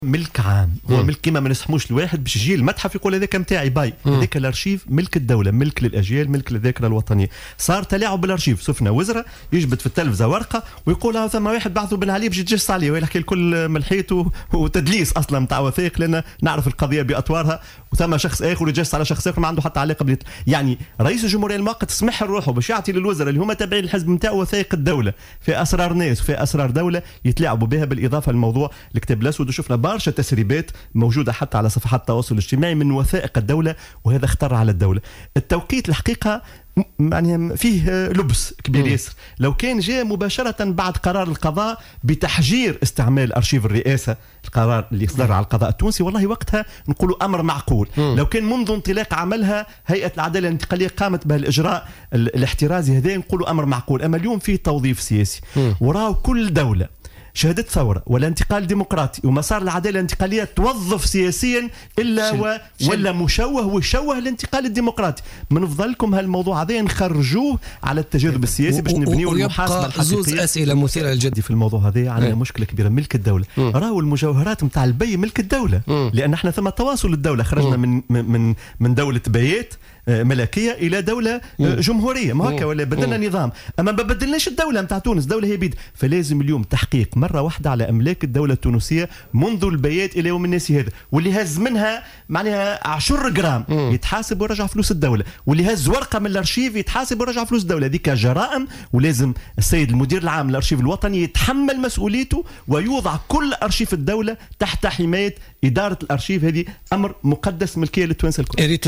دعا رئيس حزب المجد،عبد الوهاب الهاني ضيف برنامج "بوليتيكا" اليوم إلى ضرورة فتح تحقيق حول التصرّف في أرشيف رئاسة الجمهورية وفي أملاك الدولة التونسية من عهد البايات إلى اليوم.